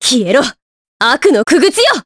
Aselica-Vox_Skill5_jp_b.wav